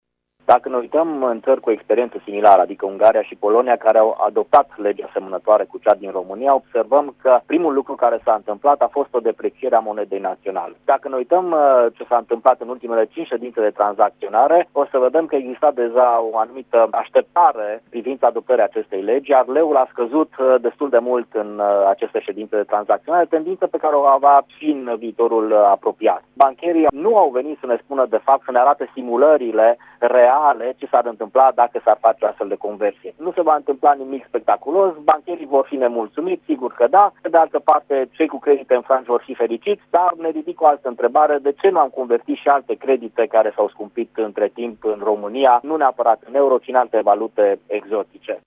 Cum va influența această decizie economia națională, ne spune analistul economic